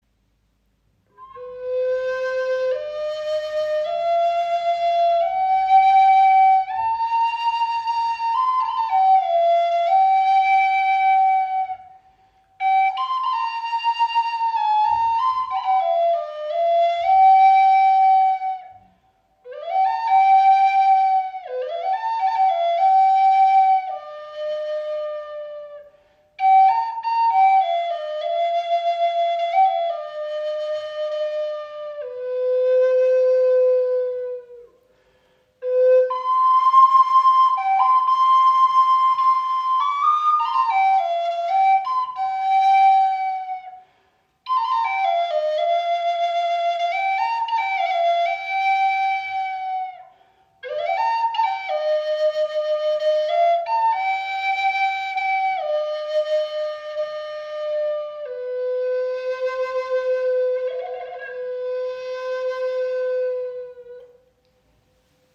• Icon Klare, kristallene Klangqualität mit grosser klanglicher Tiefe
Diese kleine Walnussflöte ist ideal für unterwegs und lässt ihren kräftigen Klang durch Wald und Berge schweben.